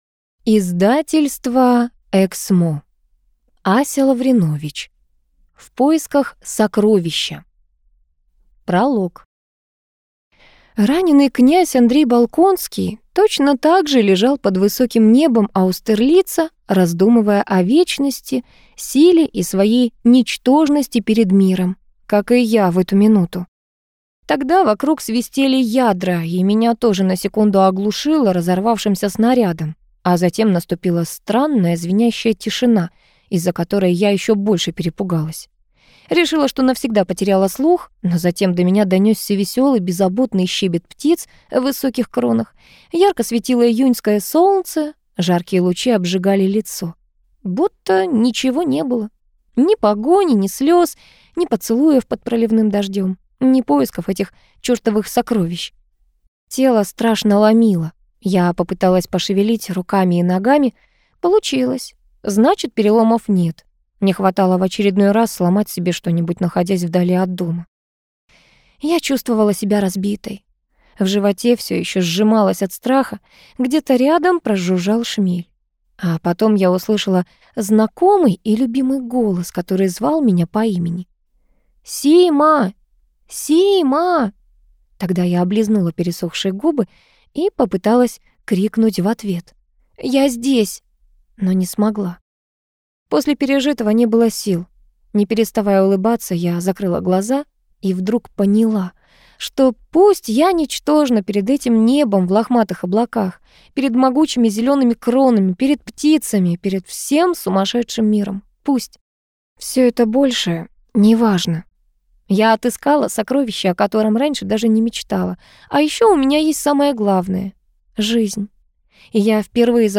Аудиокнига В поисках сокровища | Библиотека аудиокниг